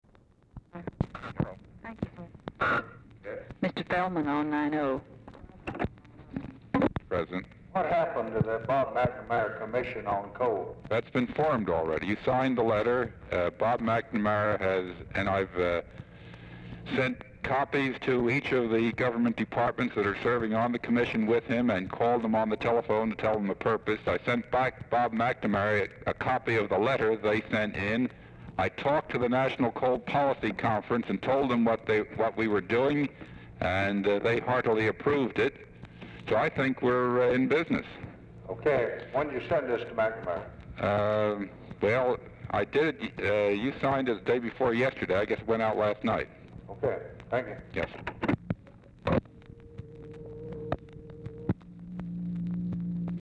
Telephone conversation # 3890, sound recording, LBJ and MYER FELDMAN, 6/24/1964, 5:15PM | Discover LBJ
Format Dictation belt
Oval Office or unknown location